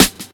DOUBZ_SNR.wav